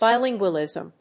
音節bì・lín・gual・ìsm 発音記号・読み方
/‐lìzm(米国英語)/
音節bi･lin･gual･ism発音記号・読み方baɪlɪ́ŋgwəlɪ̀z(ə)m
bilingualism.mp3